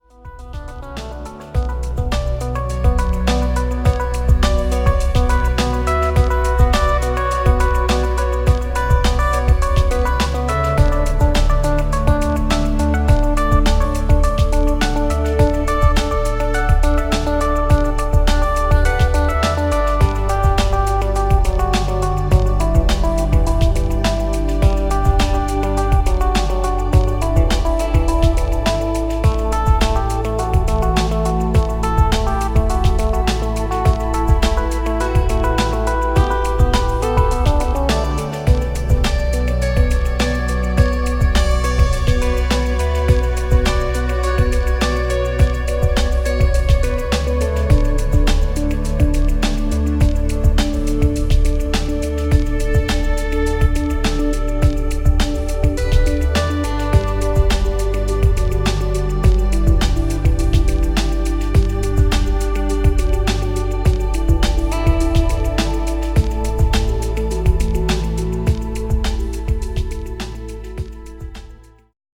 The audio with some subtle EQ based widening.